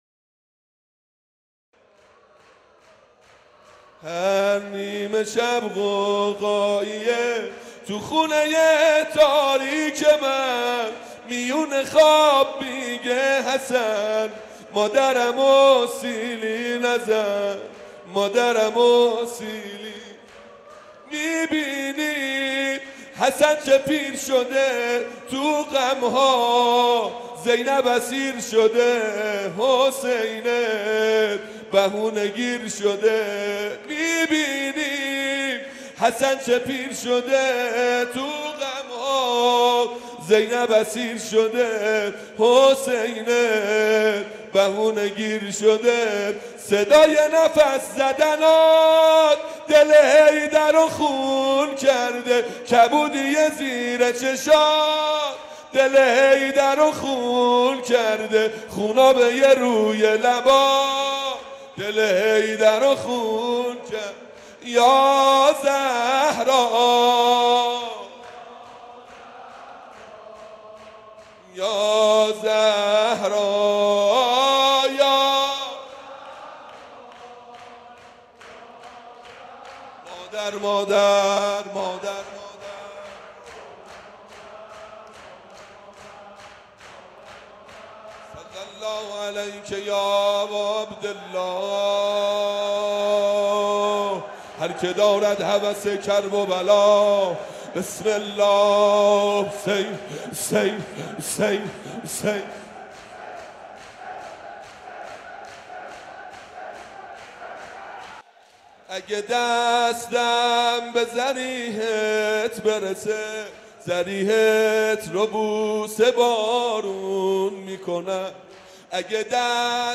شب سوم فاطمیه در مسجد ارک
ضمنا این مراسم از دوشنبه 19 لغایت 23 اسفند ماه به مدت پنج شب از نماز مغرب و عشا در مسجد ارک تهران برقرار می باشد.